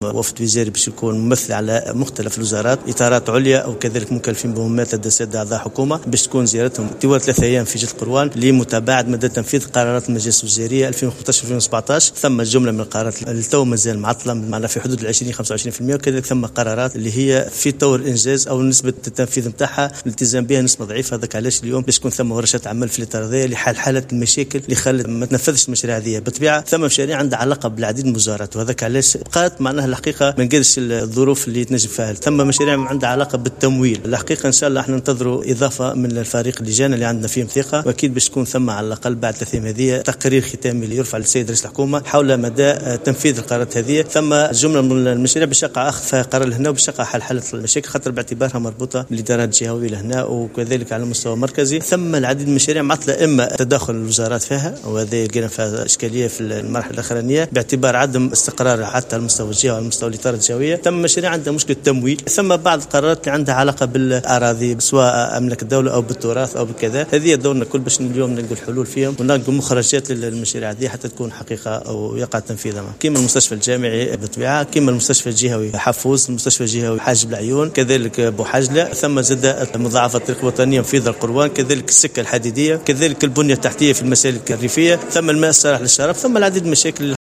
والي القيروان